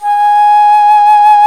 Index of /90_sSampleCDs/Roland LCDP04 Orchestral Winds/FLT_Alto Flute/FLT_A.Flt vib 2
FLT ALTOFL0L.wav